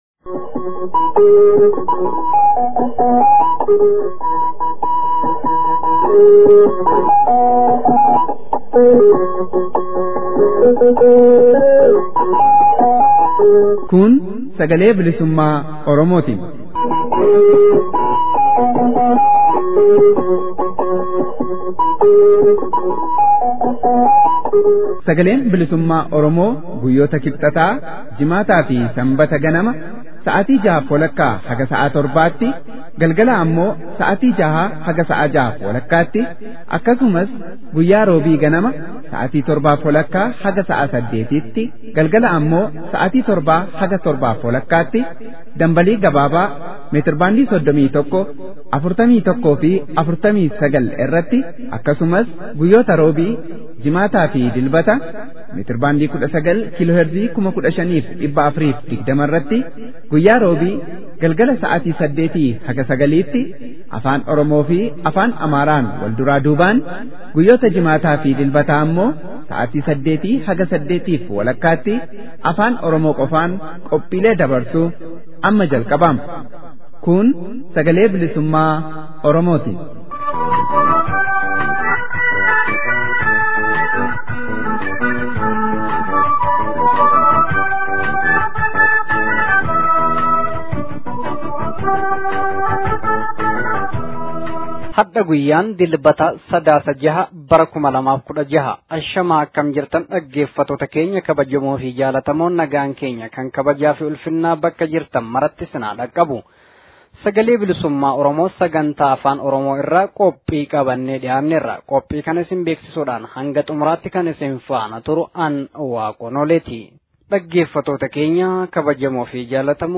SBO: Sadaasa 06 Bara 2016. Oduu, Gaaffii fi Deebii